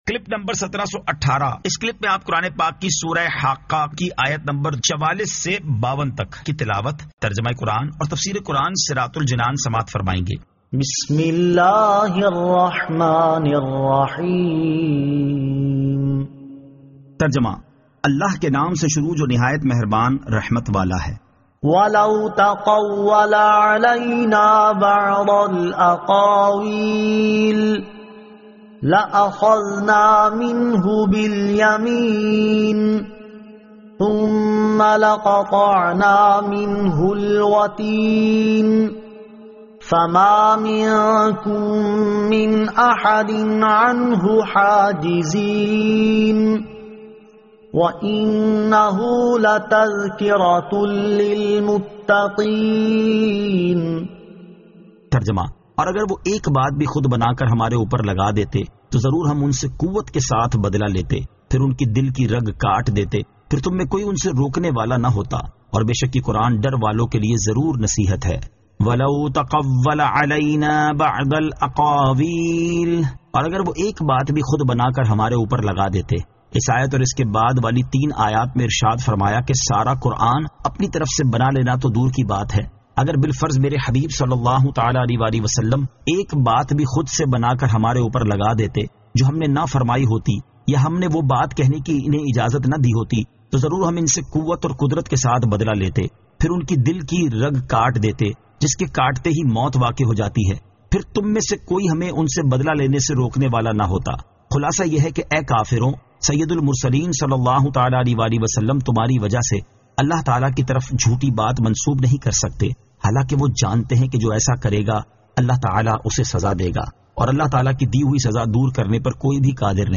Surah Al-Haqqah 44 To 52 Tilawat , Tarjama , Tafseer
2024 MP3 MP4 MP4 Share سُوَّرۃُ الحَاقَّۃِ آیت 44 تا 52 تلاوت ، ترجمہ ، تفسیر ۔